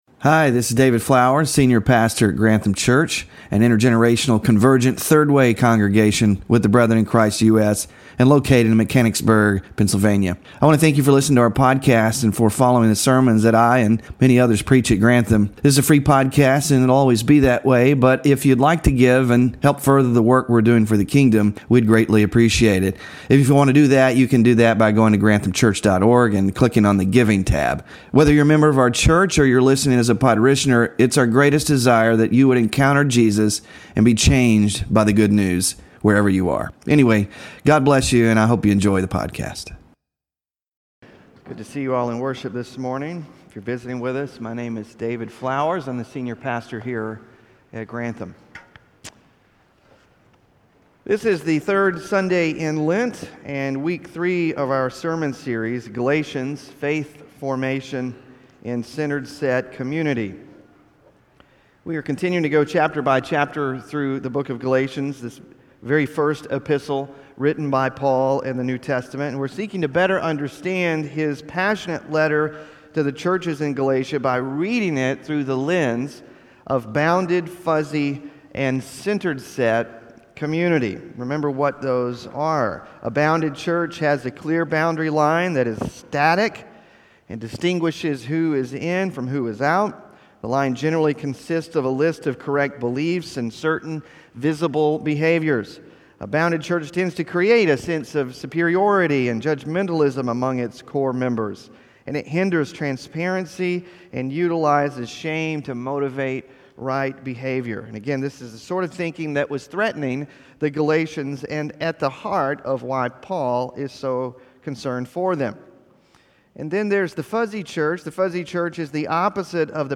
WORSHIP RESOURCES BELONGING TO CHRIST THROUGH FAITH SERMON SLIDES (3 of 6) SMALL GROUP QUESTIONS (3-23-25) BULLETIN WITH BAPTISM INSERT (3-23-25)